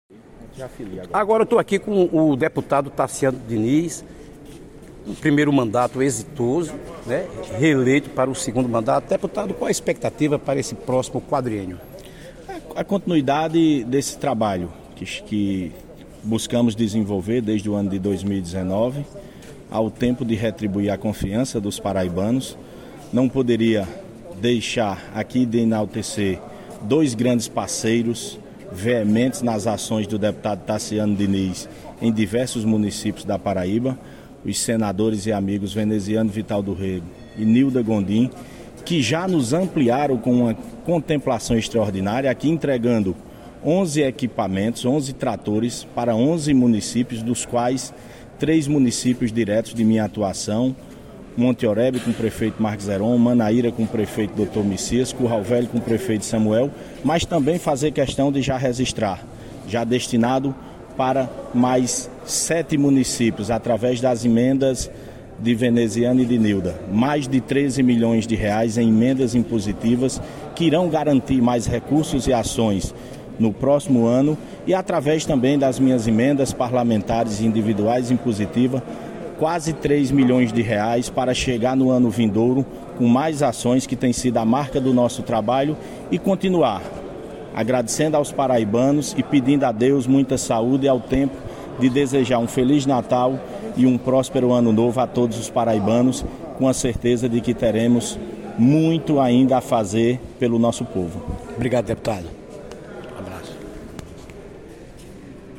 Com exclusividade ao programa 360 Graus, da Rede 360 News e rádio 100.5 FM, ele garantiu que dará continuidade ao trabalho desenvolvido na Assembleia Legislativa da Paraíba (ALPB) a fim de retribuir a confiança dos paraibanos.